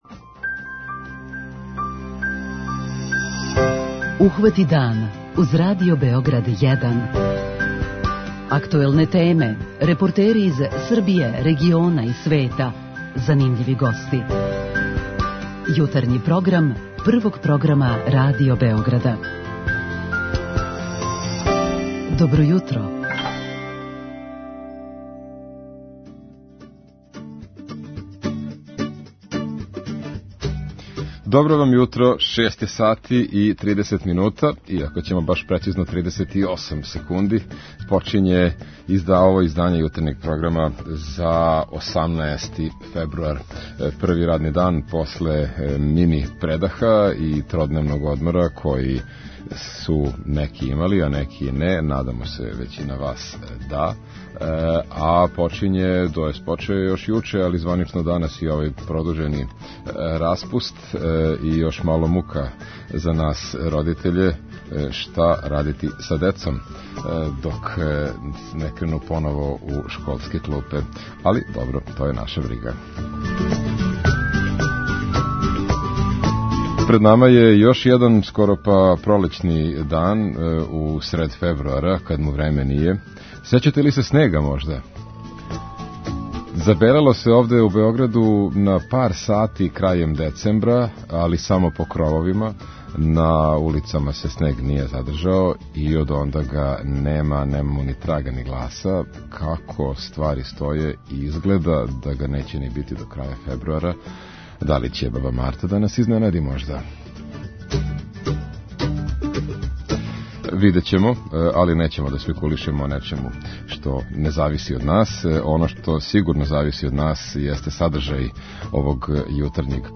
У питању јутра и ви ћете моћи да поставите питање нашем госту.